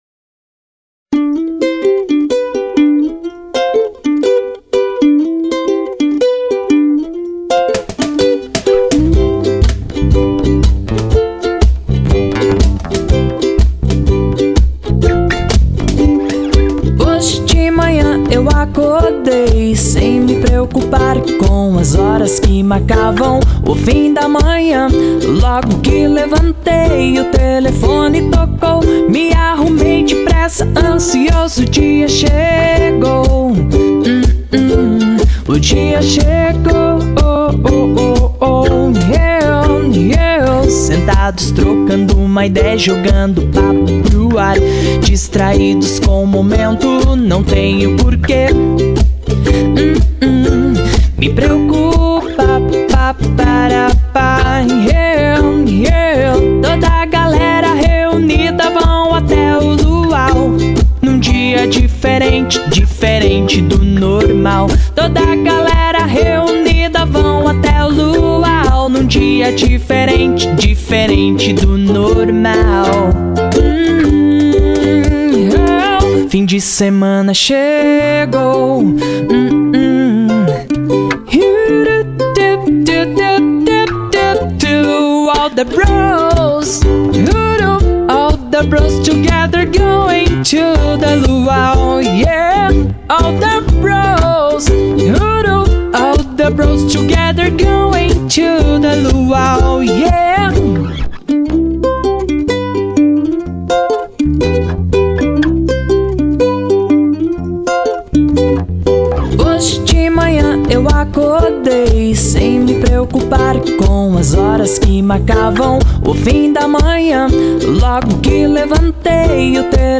EstiloSurf Music